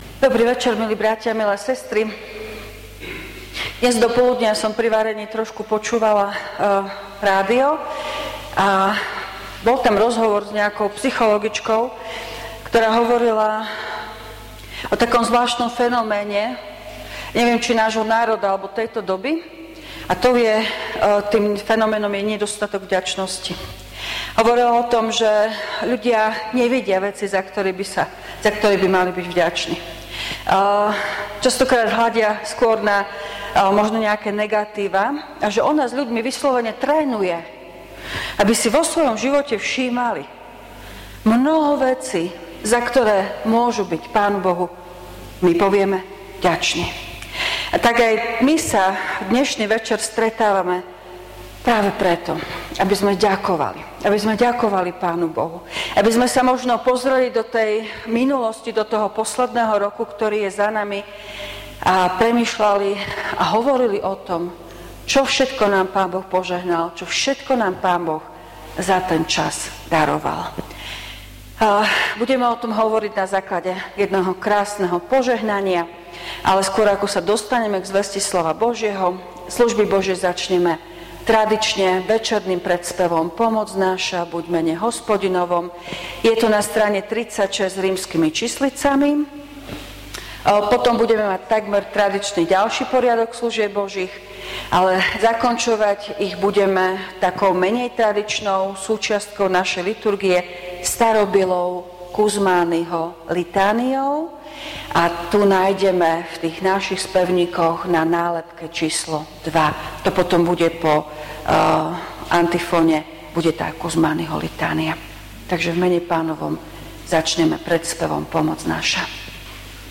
V nasledovnom článku si môžete vypočuť zvukový záznam zo služieb Božích – Závierka občianskeho roka.